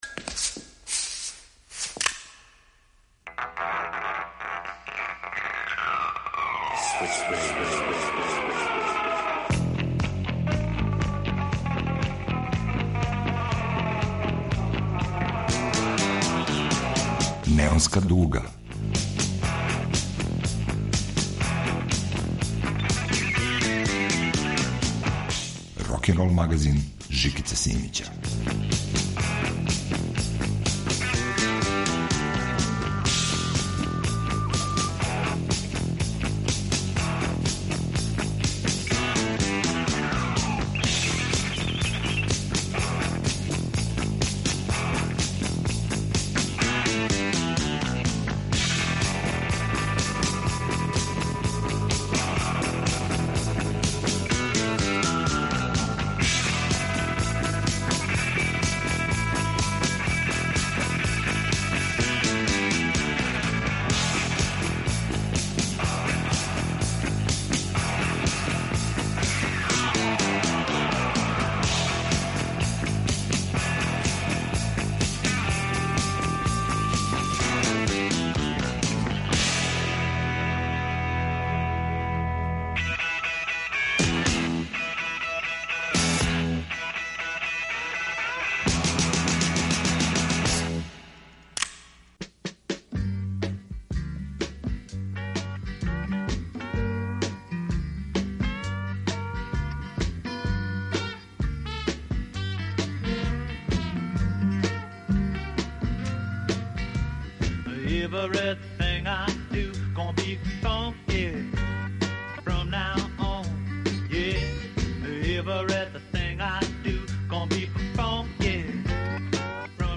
Неонска дуга - рокенрол магазин